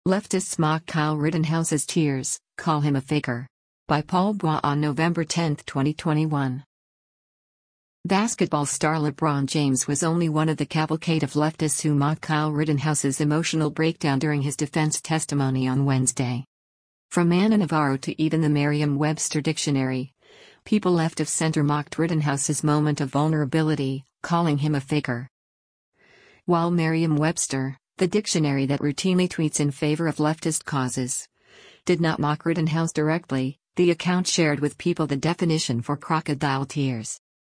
KENOSHA, WISCONSIN - NOVEMBER 10: Kyle Rittenhouse breaks down on the stand as he testifie